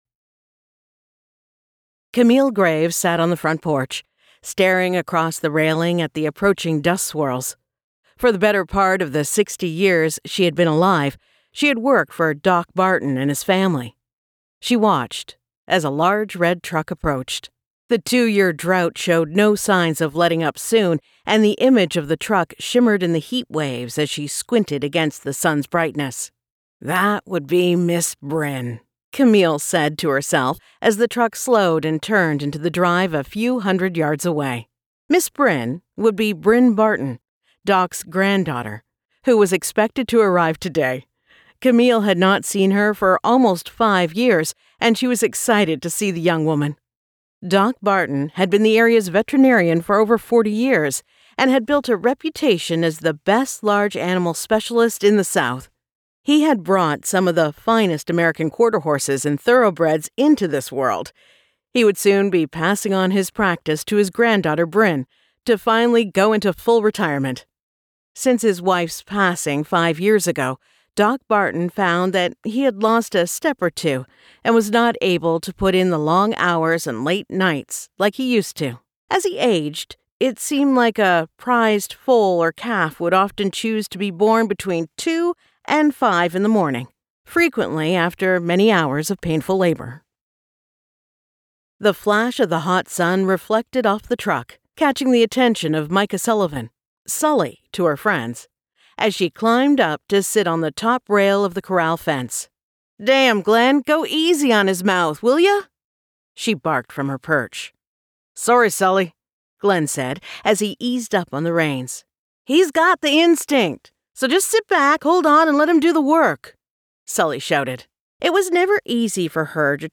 Sullivan’s Trace by Ali Spooner [Audiobook]